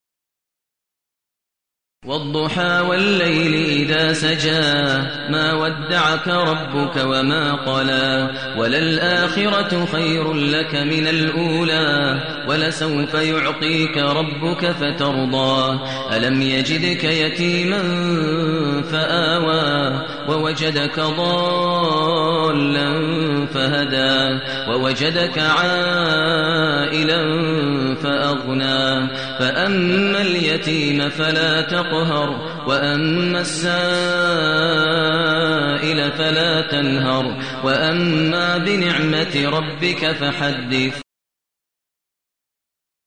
المكان: المسجد الحرام الشيخ: فضيلة الشيخ ماهر المعيقلي فضيلة الشيخ ماهر المعيقلي الضحى